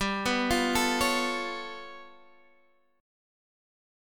G9b5 Chord